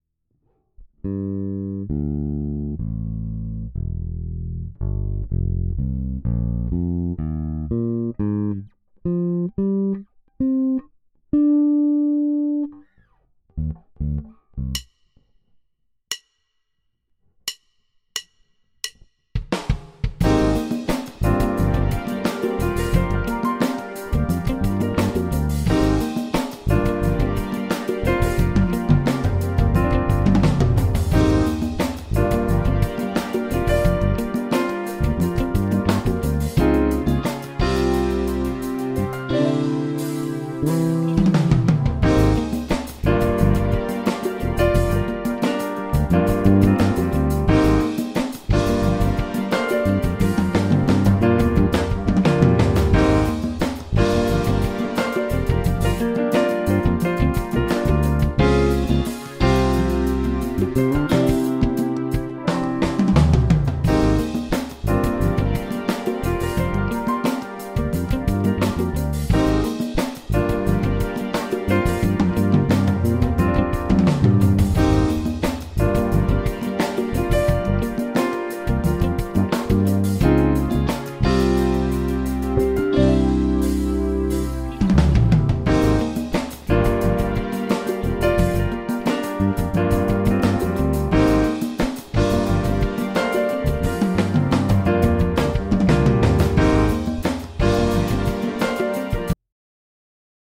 Jedná se vzhledem o obyčejnou P bass, avšak s neobvyklou kombinací dřev a pár věcmi udělanými jinak.
Kdybych to měl popsat tak, jak slyším, tak to fakt hrne, ale opravdu moc příjemně smile.